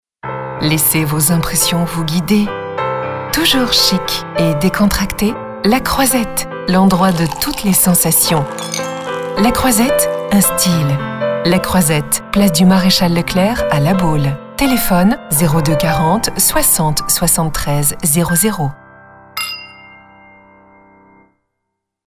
Sprechprobe: Industrie (Muttersprache):
My voice is medium, clear, smiling, with well-educated, well pronounced accent.